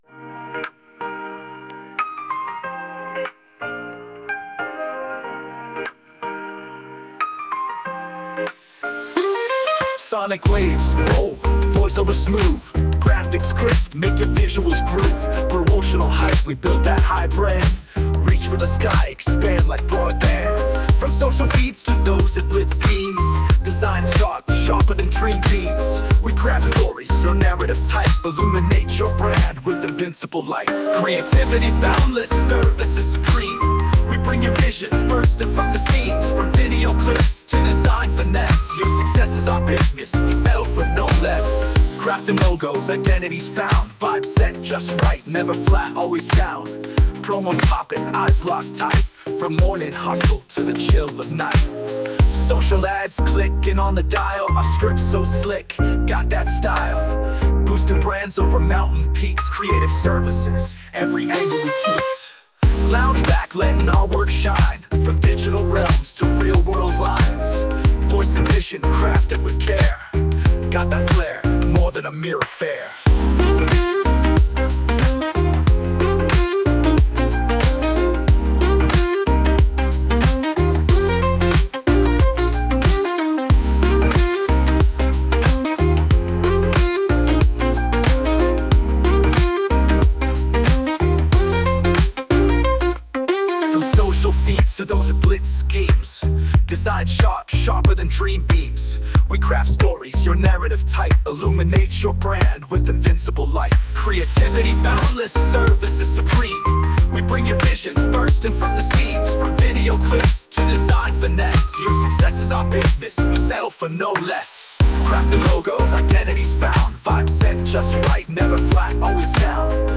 Music Genre: Lounge Song (Male Singer)